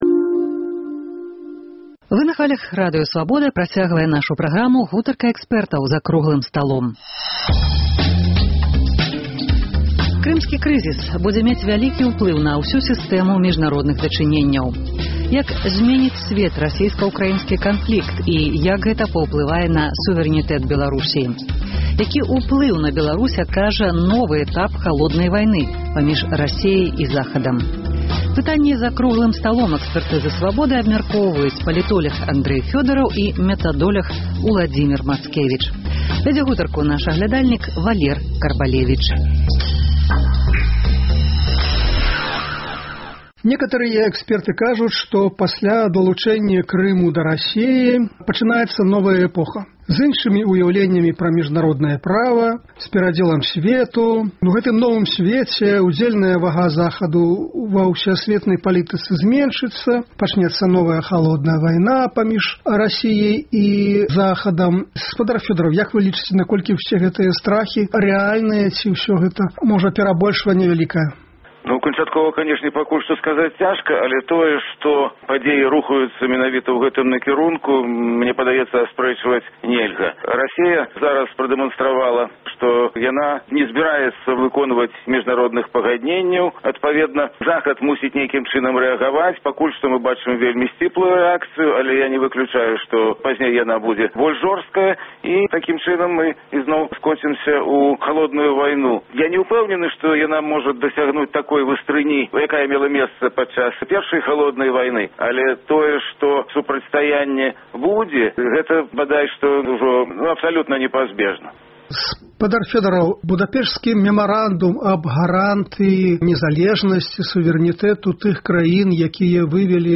Гэтыя пытаньні абмяркоўваюць за круглым сталом